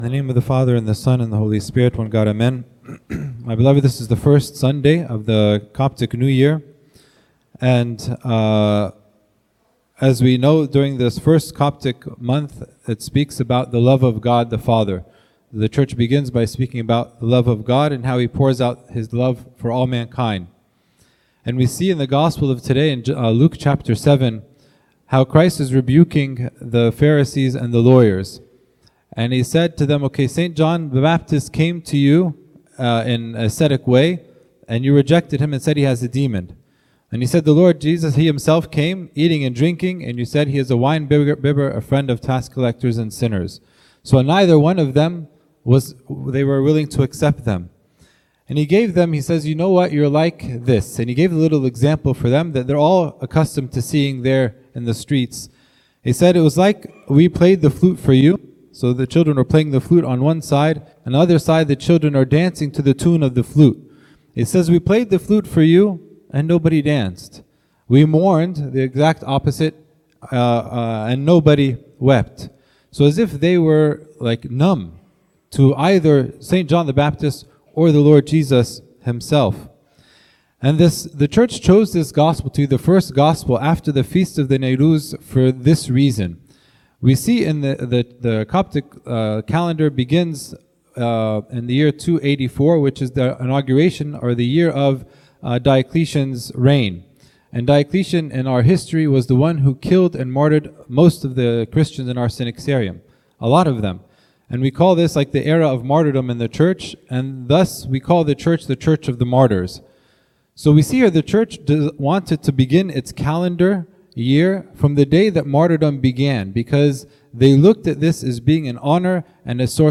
Content Type: Sermon